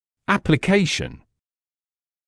[ˌæplɪ’keɪʃn][ˌэпли’кэйшн]приложение (часто о силе); применение; использование; нанесение (мази и пр.)